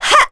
Chrisha-Vox_Attack2.wav